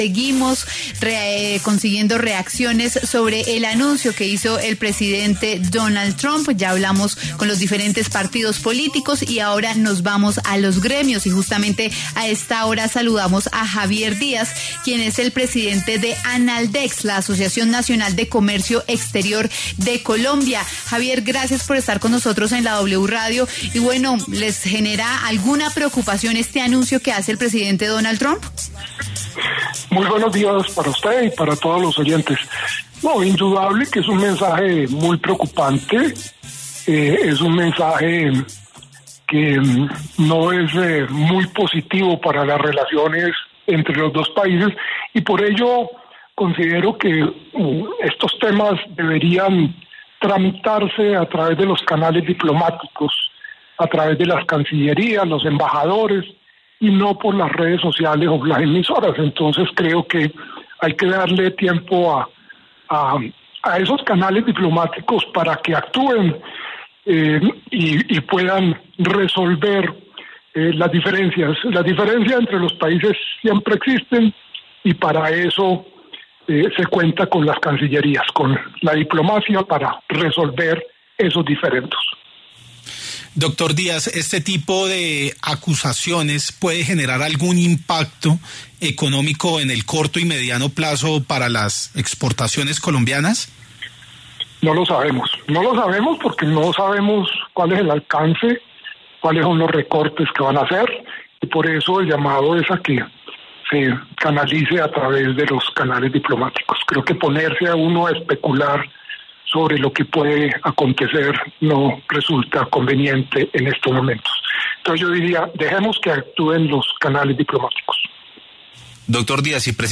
En diálogo con W Fin de Semana